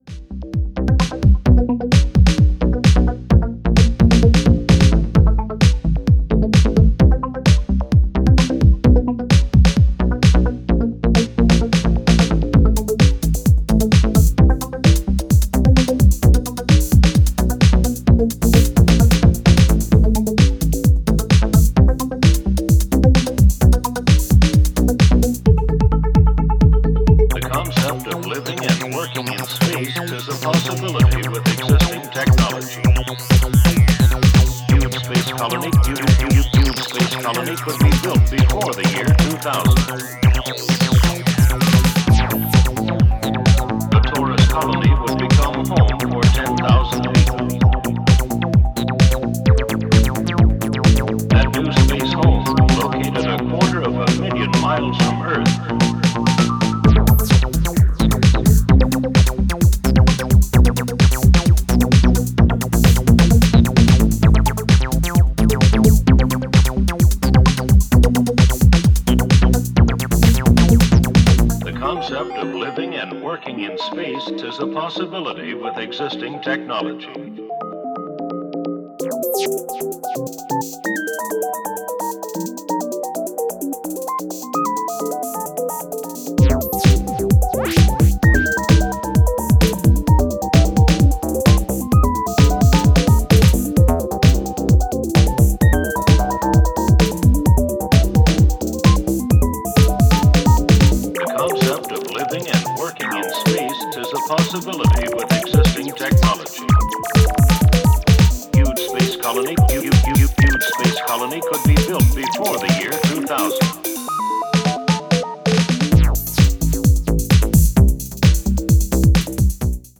gets more and more emotional